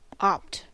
Ääntäminen
IPA: [a.dɔp.te]